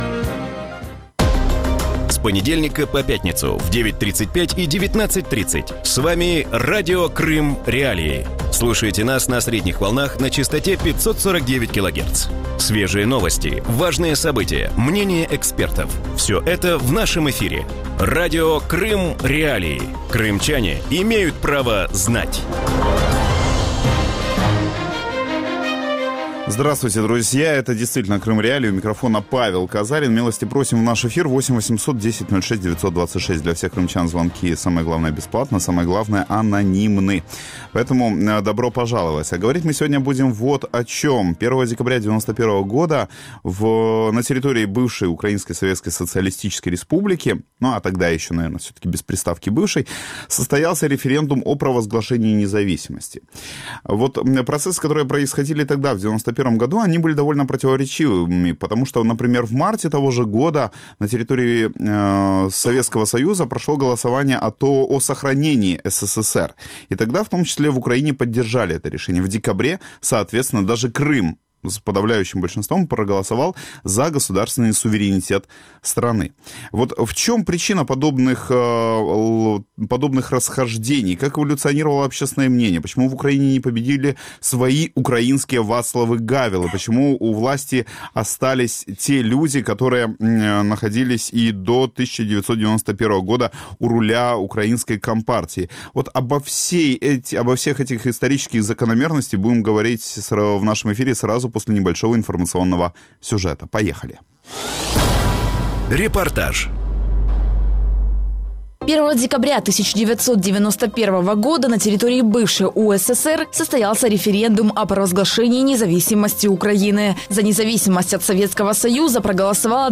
У вечірньому ефірі Радіо Крим.Реалії говорять про 25-у річницю Всеукраїнського референдуму на підтримку Акта проголошення незалежності України. Чому абсолютна більшість українців, в тому числі і в Криму, підтримали незалежність України і яких помилок можна було уникнути на початку шляху?